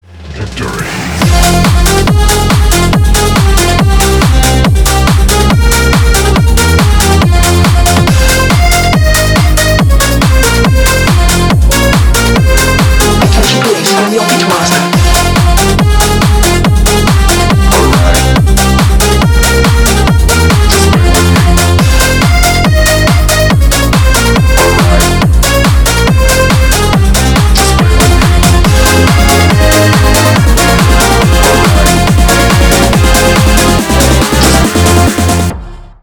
ремиксы
поп